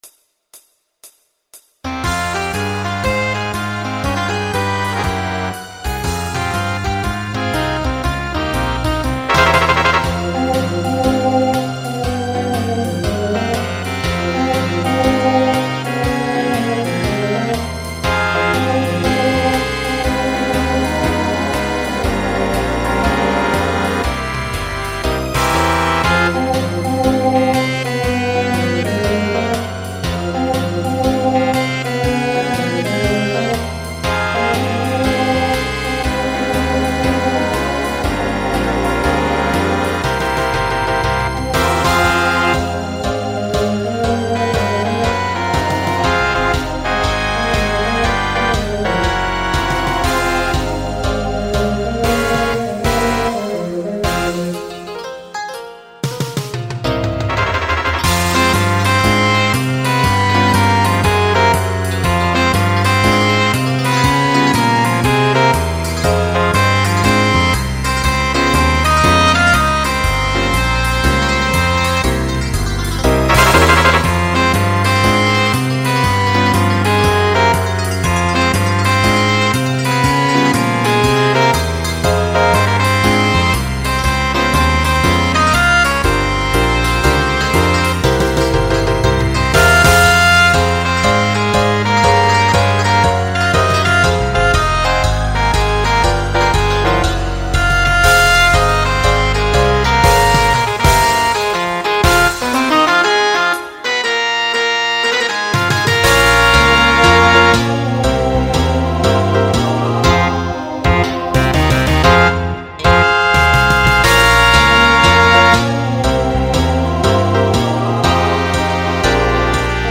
Voicing TTB Instrumental combo Genre Swing/Jazz
Mid-tempo